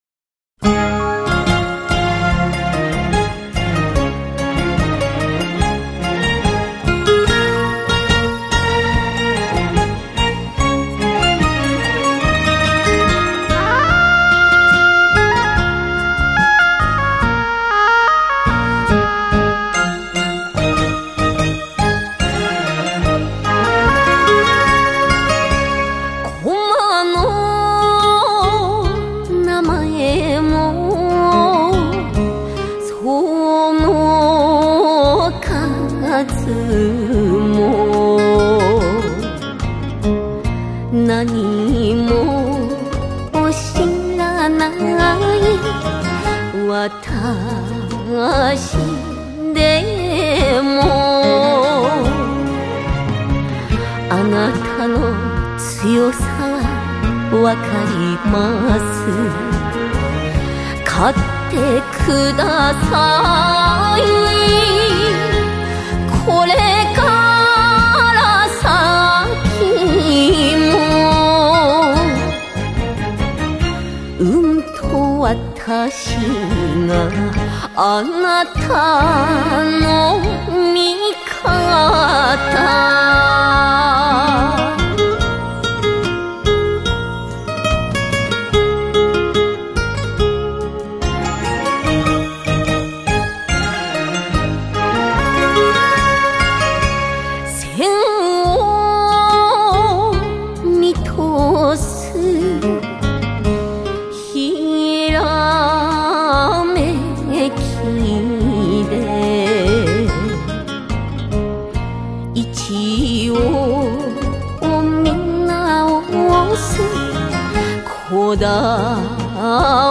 演歌生涯是一个梦